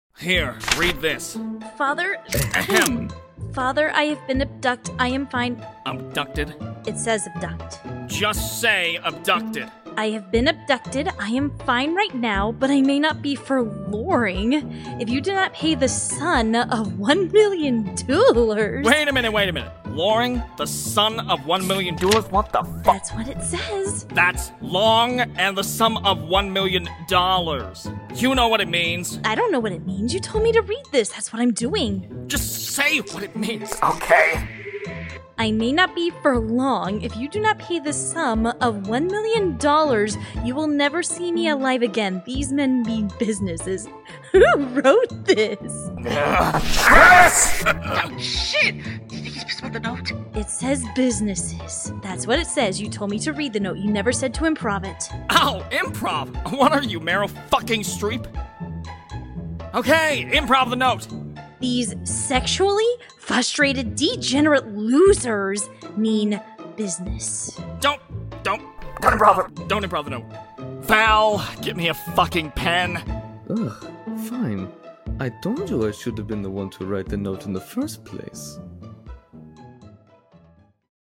Animatic Voiceover/Dub